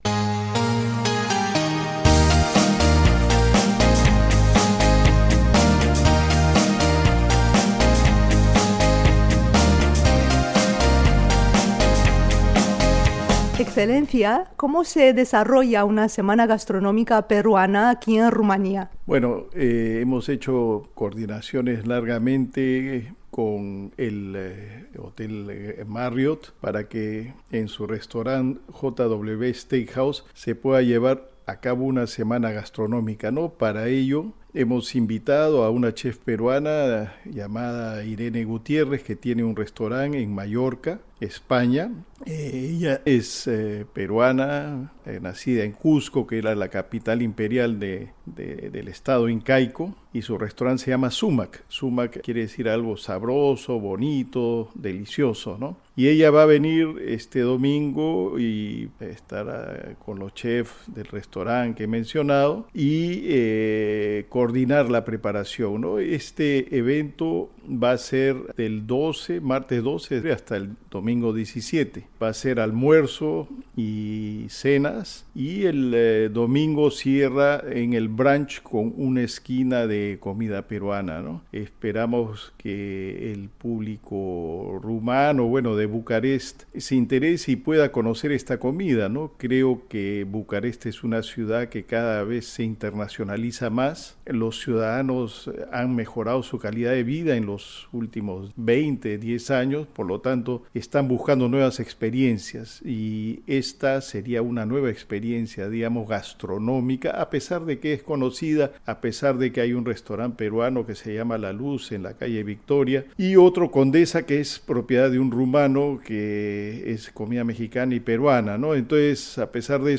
S.E. el Sr. Félix Denegri Boza, Embajador del Perú en Rumanía, ha hablado sobre este evento en una entrevista difundida por el programa Paseo cultural.